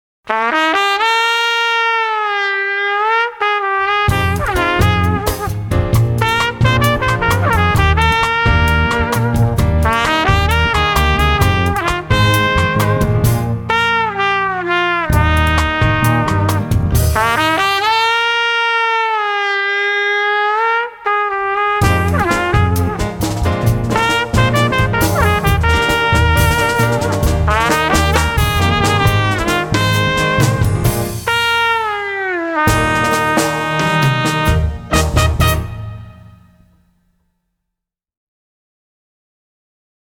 Hrajeme živý jazz, swing